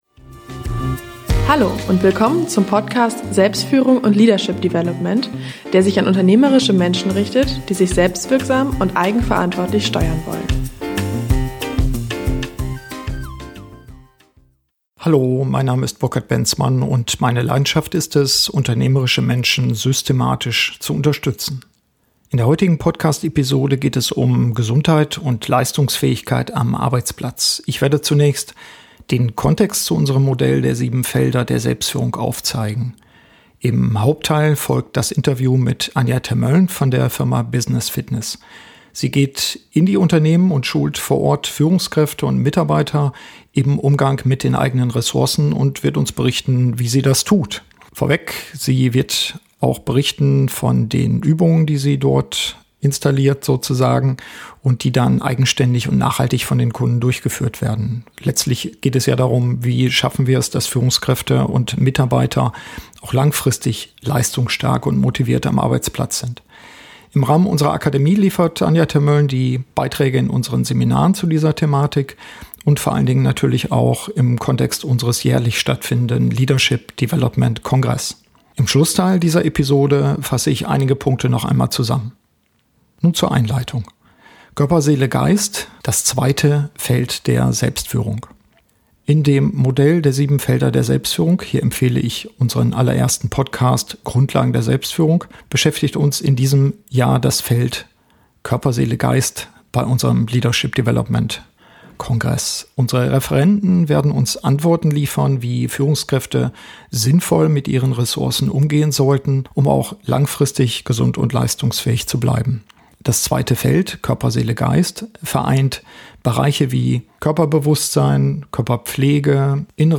Vor dem Interview erläutere ich zunächst den Bezug zum Modell der Sieben Felder der Selbstführung. Im Hauptteil folgt das Interview und im Schlussteil dieser Podcast-Episode fasse ich einige Aspekte noch einmal zusammen.